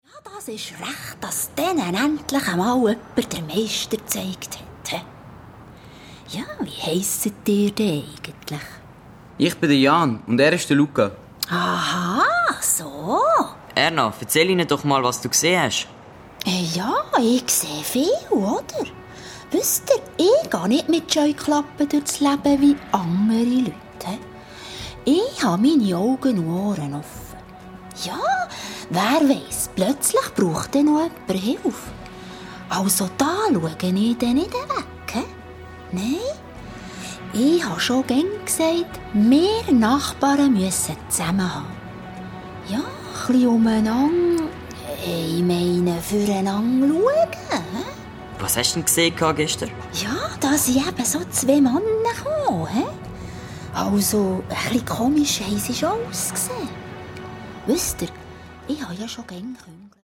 Hörspiel ab 8 Jahren